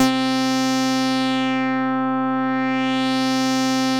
Index of /90_sSampleCDs/Trance_Explosion_Vol1/Instrument Multi-samples/Wasp Dark Lead
C4_wasp_dark_lead.wav